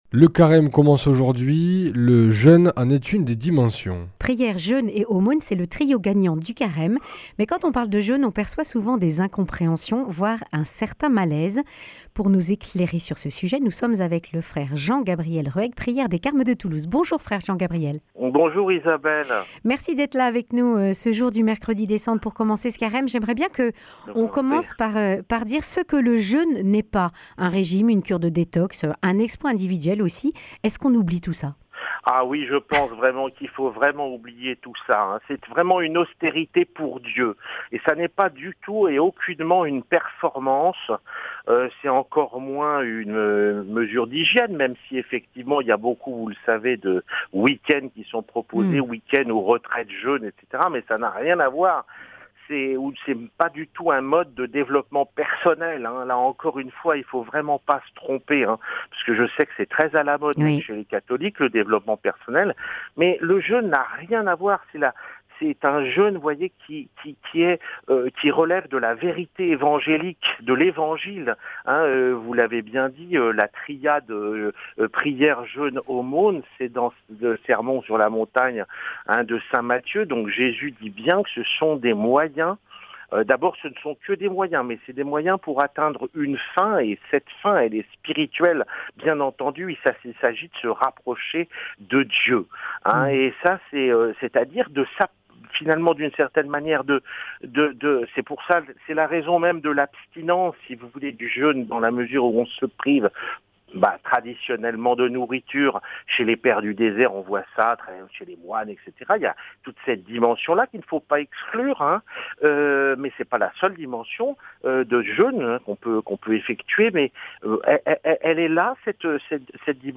Accueil \ Emissions \ Information \ Régionale \ Le grand entretien \ Le jeûne, régime ou exploit ?